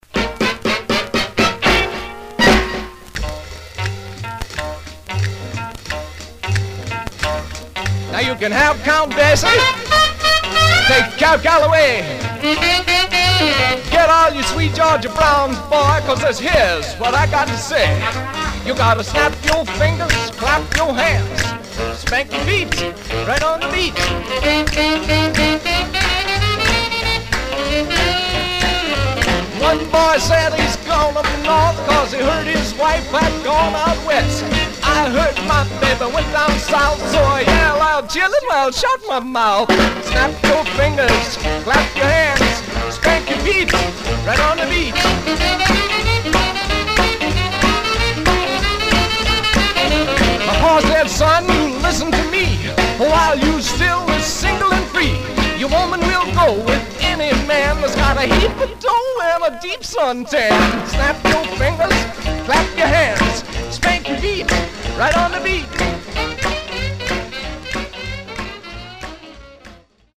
Surface noise/wear
Mono
Rockabilly